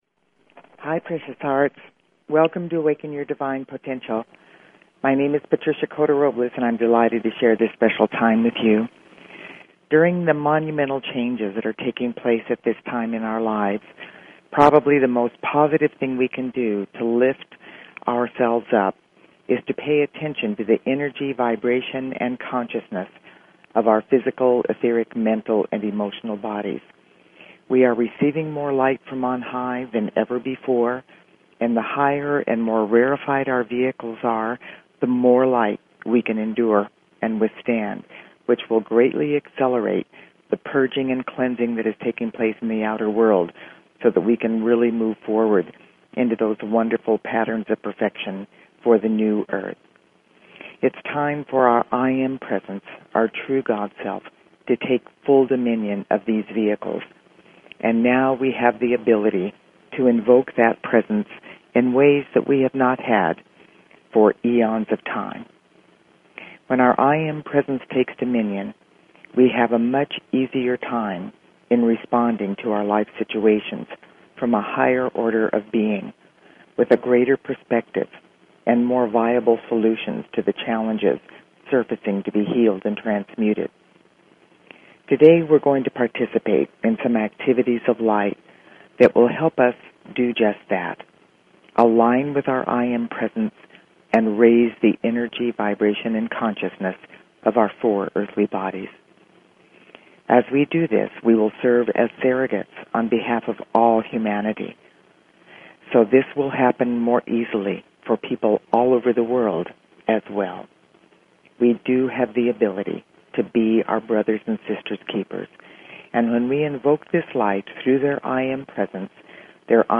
Talk Show Episode, Audio Podcast, Awaken_Your_Divine_Potential and Courtesy of BBS Radio on , show guests , about , categorized as